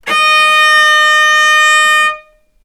vc-D#5-ff.AIF